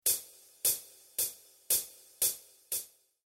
Mikrofonierung der Hi-Hat
Ein Abstand von ca. zehn Zentimeter über der Spieloberfläche und eine Neigung von ca. 45 Grad mit Ausrichtung auf diesen Anschlagbereich sind eine gute Ausgangsbasis für eine verzerrungsfreie Übertragung des Hi-Hat-Klangs.
Der Klang in der Nähe der Kuppe wird insgesamt dünner und heller sein, zum Rand hin wird der Klang voluminöser und etwas dunkler werden.
HiHat Rand